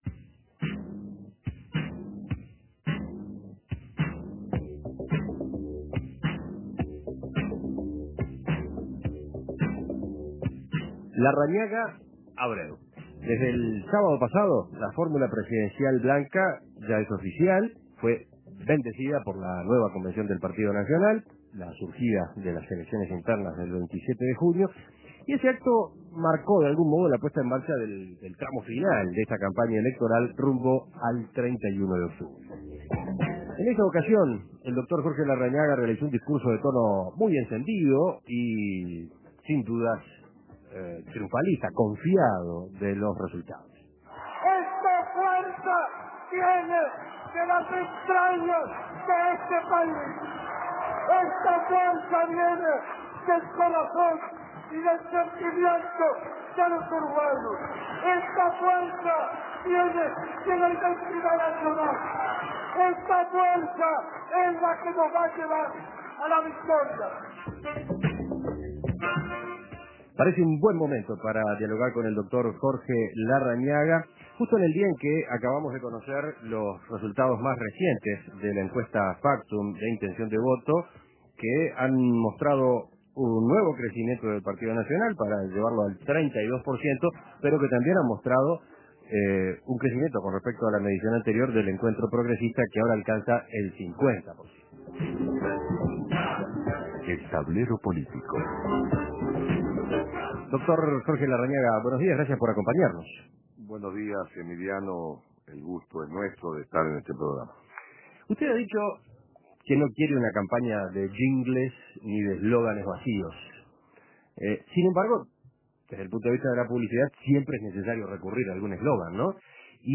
Después de la entrevista En Perspectiva, los contertulios dialogan con el candidato a la Presidencia por el Partido Nacional, Jorge Larrañaga